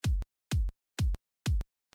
Clicken am Ende des Samples
Wenn ich es einfach anklicke und mit dem WMP abpiele habe ich am Ende des Samples ein clicken.
Wenn ich, in diesem Fall das Bassdrum-Sample (Wave), in meine DAW lade und abspiele, klingt alles normal.